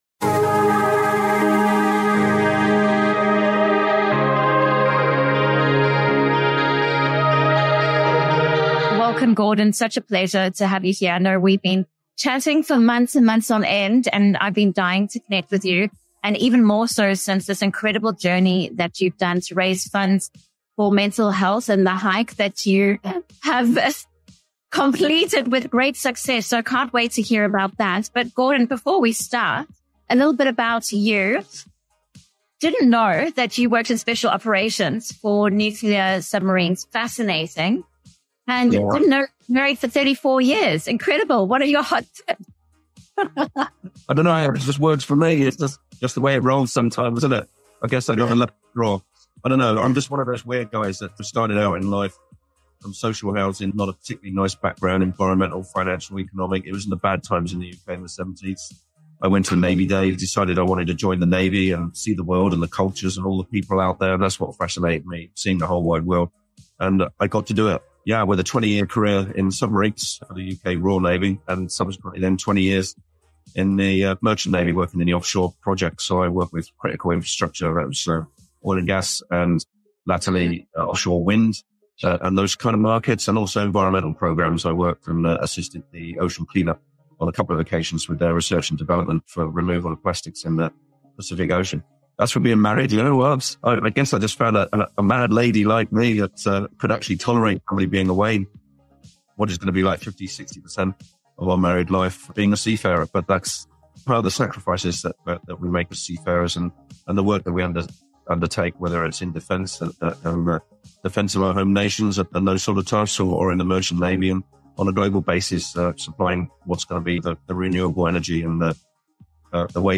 Join us in this insightful conversation on mental health in the maritime world.